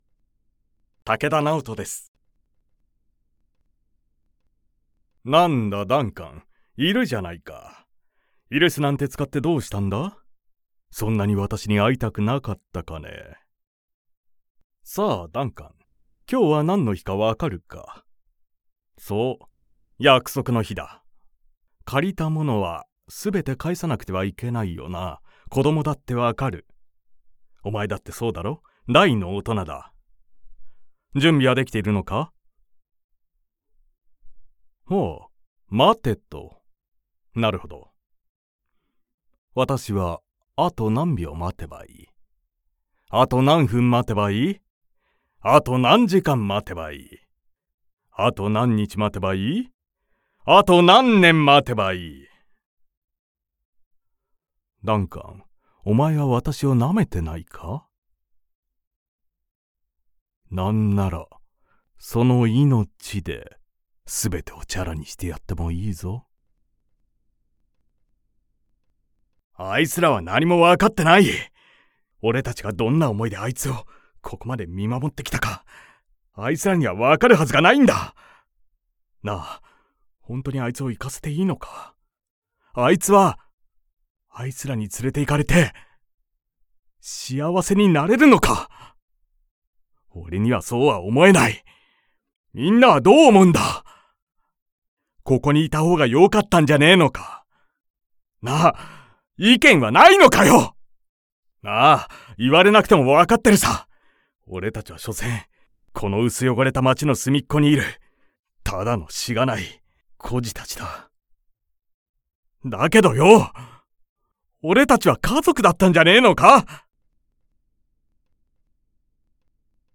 所属声優男性
サンプルボイス